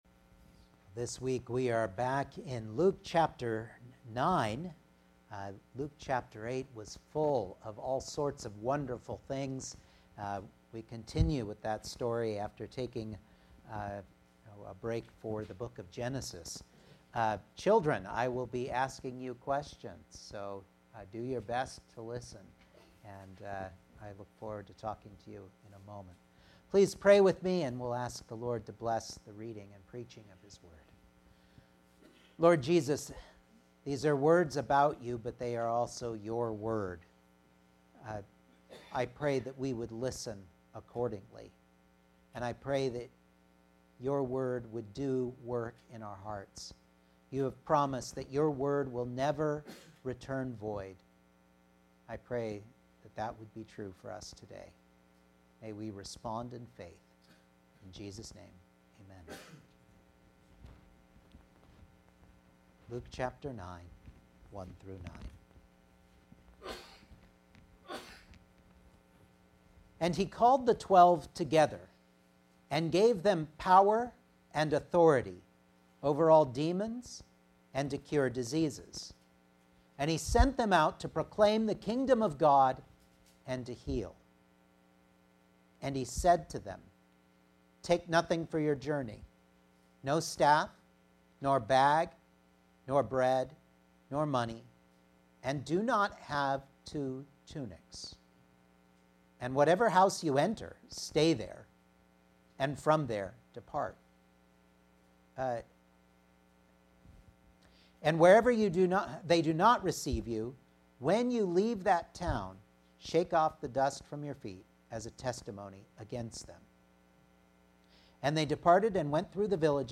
Luke 9:1-9 Service Type: Sunday Morning Outline